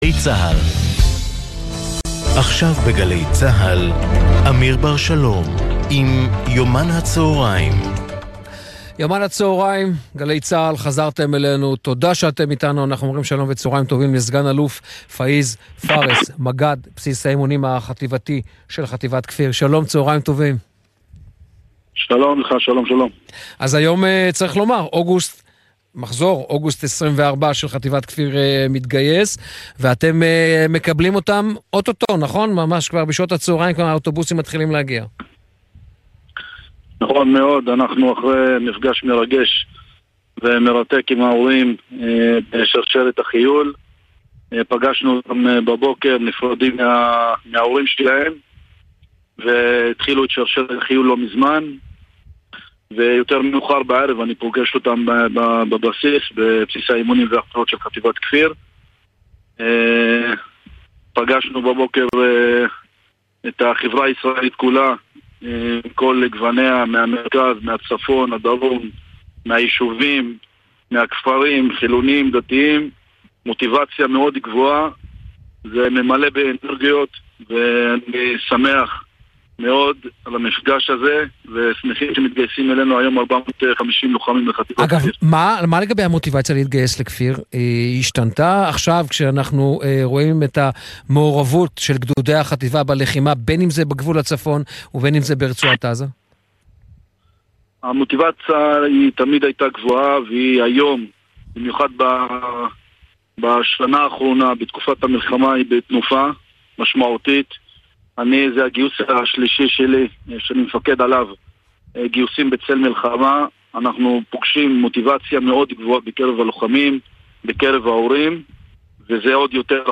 ראיון בגל"צ